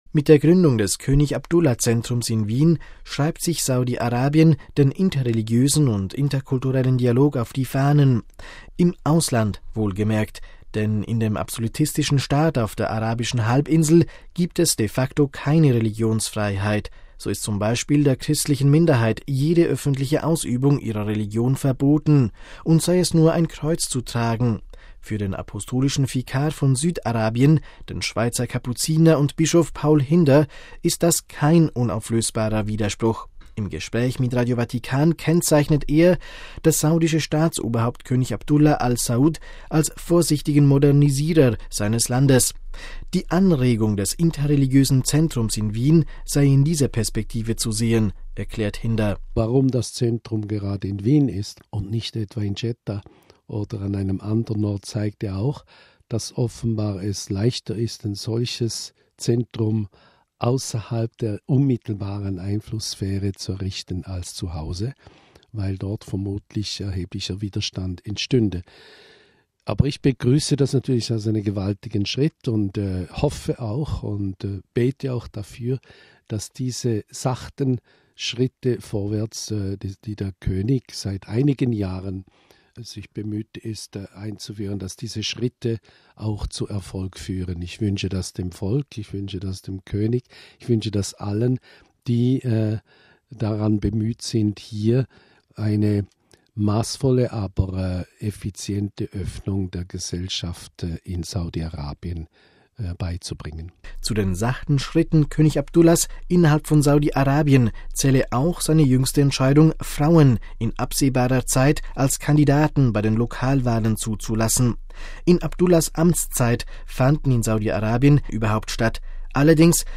Im Gespräch mit Radio Vatikan kennzeichnet er das saudische Staatsoberhaupt König Abdullah Al Saud als „vorsichtigen Modernisierer“ seines Landes.